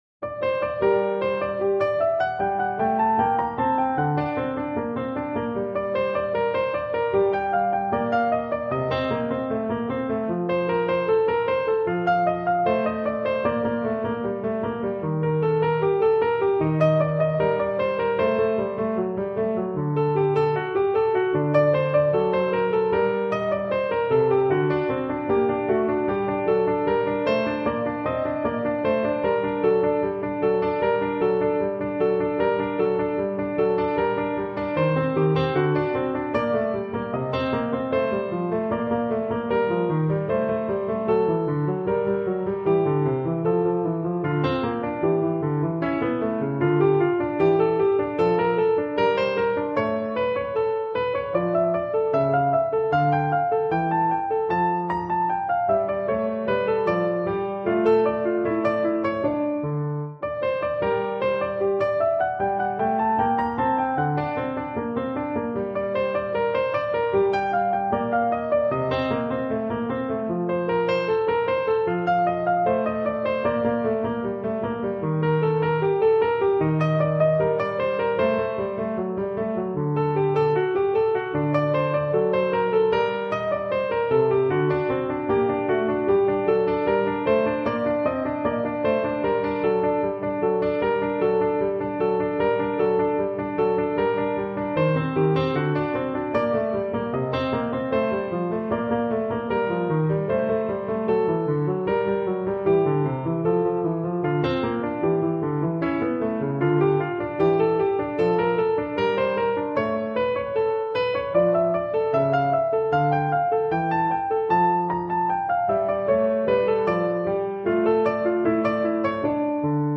• Duet (Violin / Viola)